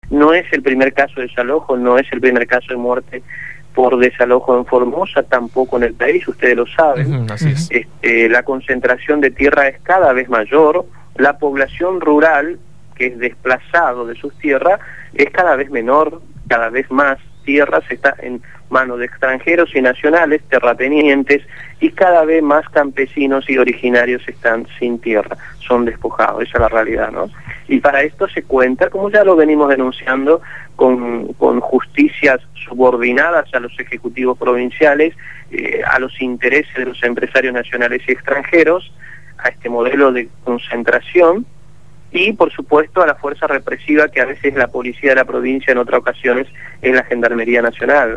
fue entrevistado en «Desde el Barrio»